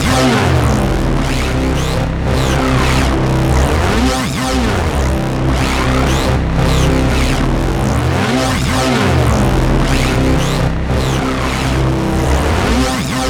SAVAGEMOOG.wav